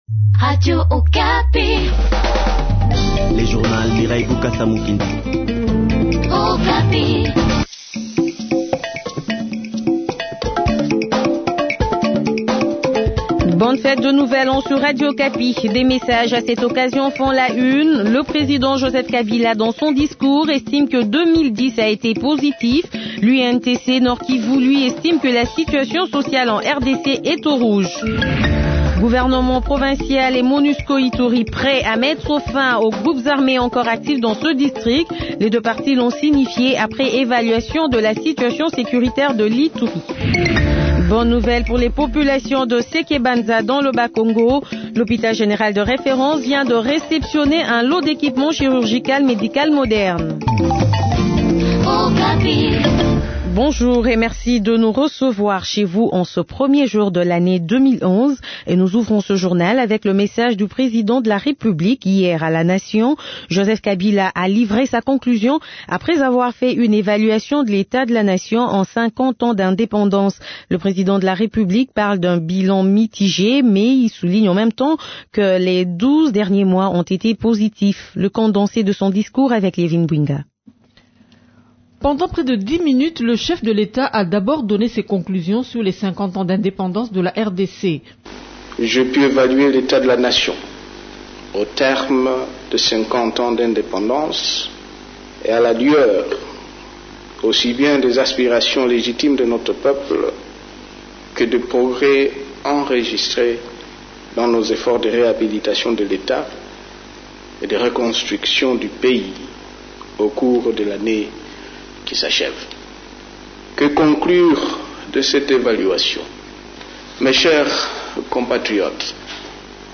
Journal Français Matin